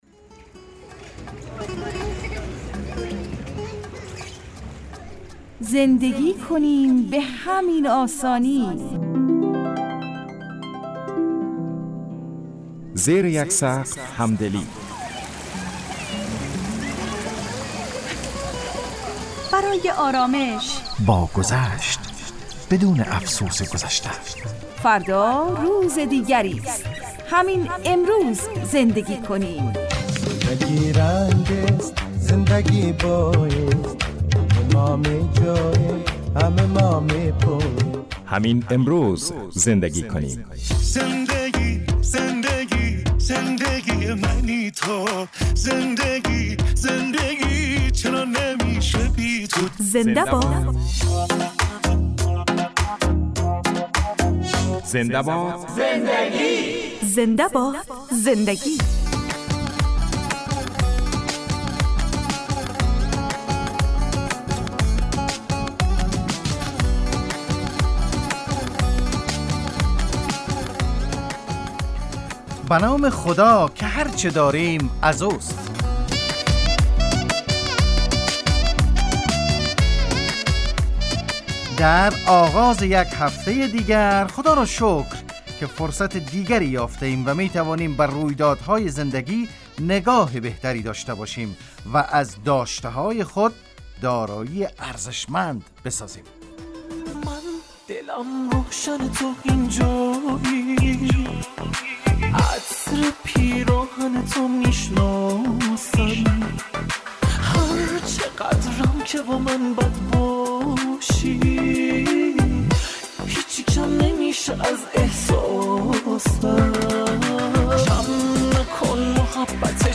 برنامه خانوادگی رادیو دری